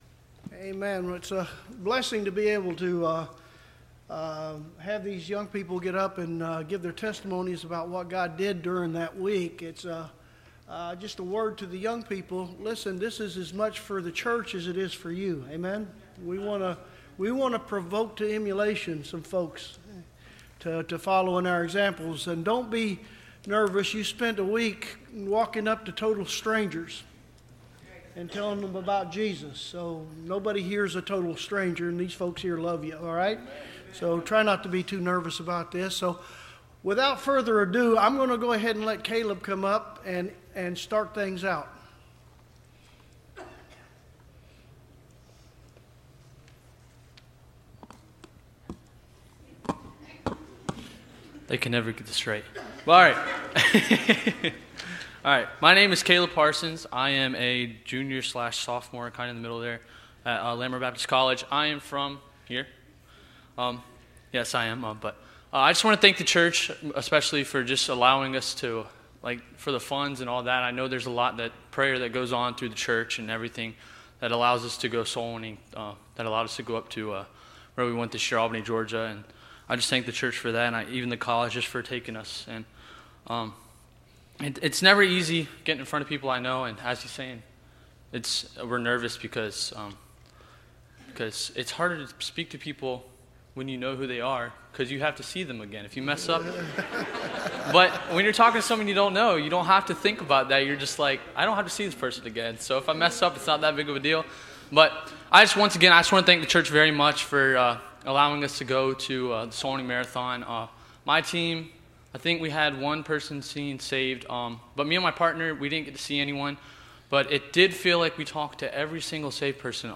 Soulwinning Marathon Testimonies – Landmark Baptist Church
Service Type: Wednesday College